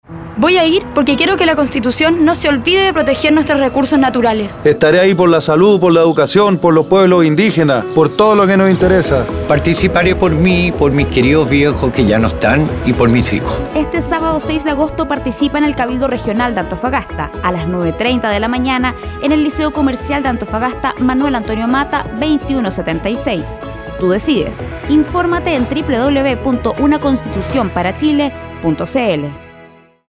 Audio promoción testimonial con detallada información de hora y lugar de realización de los cabildos regionales, Región de Antofagasta 2.